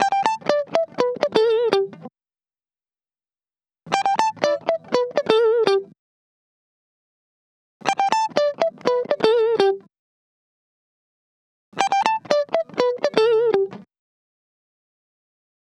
jc_cw_122_guitar_melody_hi_loop_yogi_two_Emin